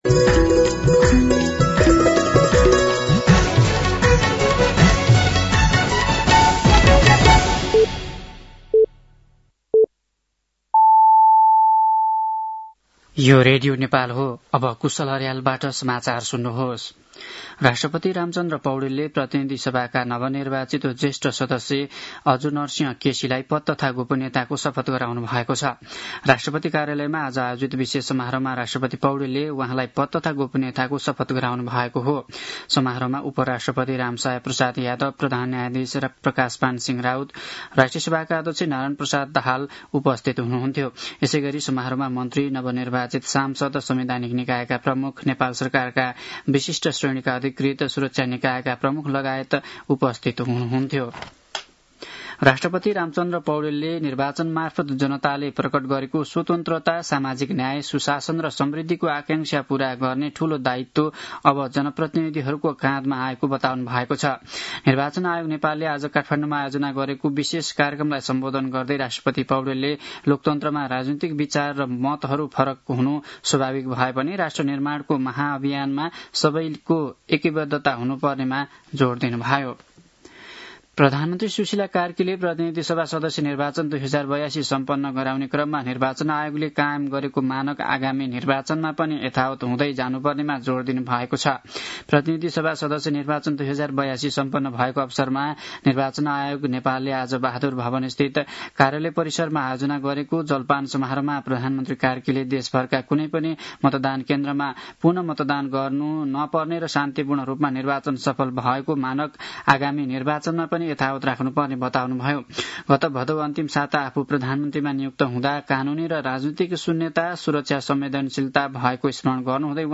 साँझ ५ बजेको नेपाली समाचार : ११ चैत , २०८२